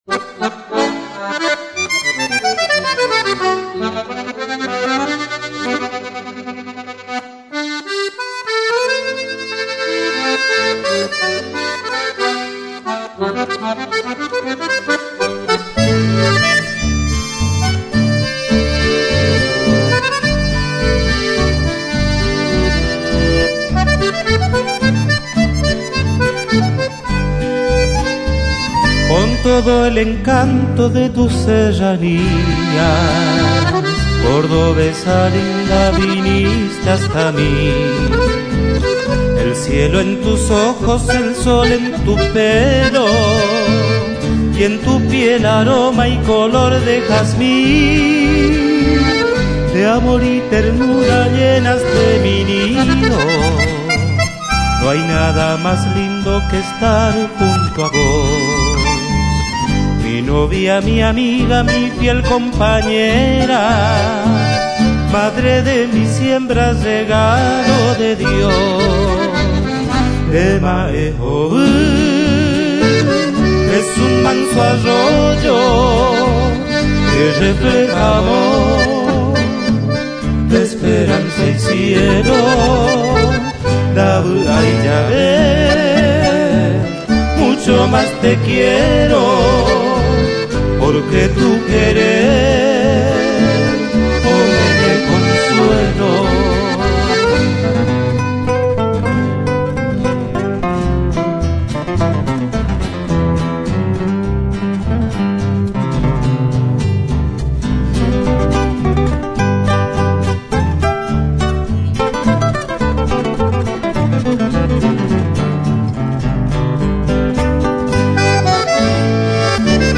Chamamé